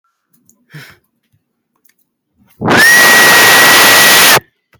Whistle BASS BOOSTED